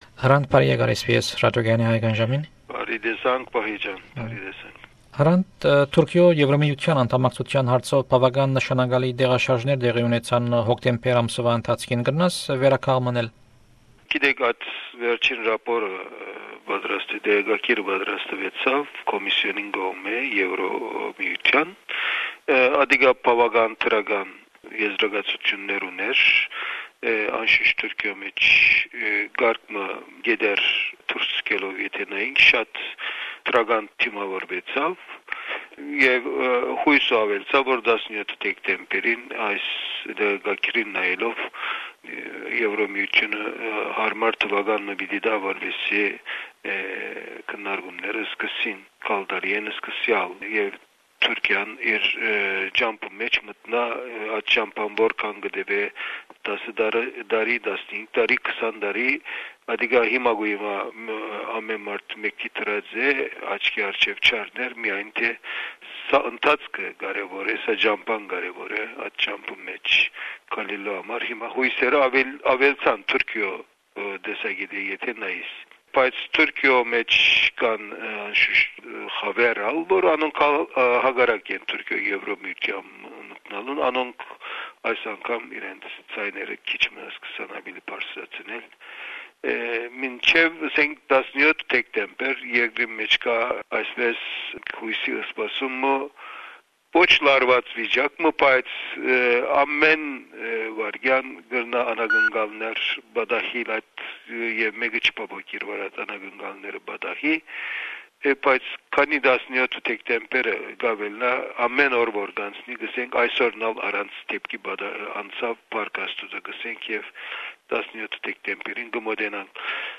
Հրանդ Տինքի յիշատակին: Սոյն հարցազրոյցը արձանագրուած է Նոյեմբեր 2004ին: